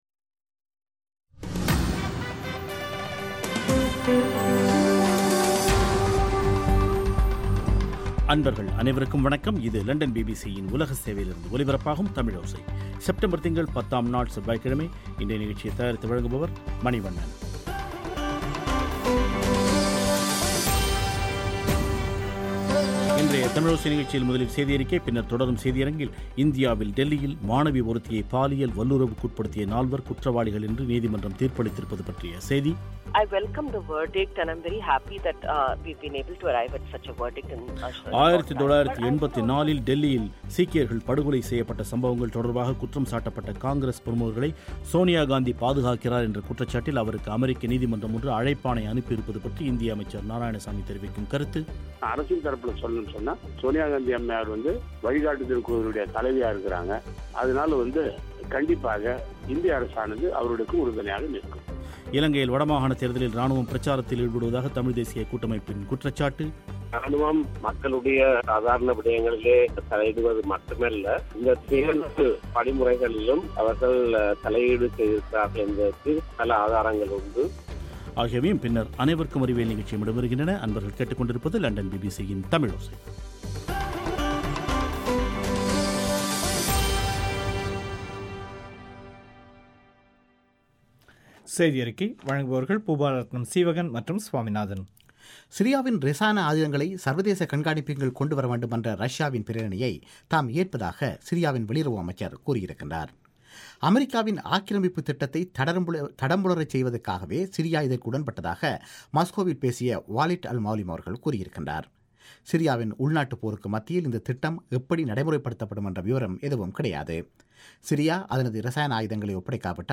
செய்தி அறிக்கை